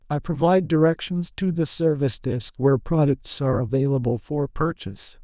disappointed.wav